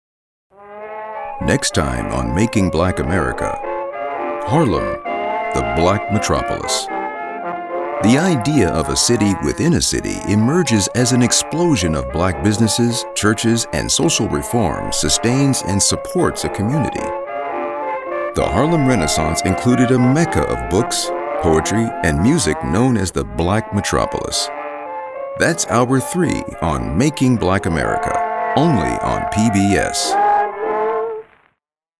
Warm, wise and comforting.
Documentary Style
General American, African American, Western, South and New York accents.
Middle Aged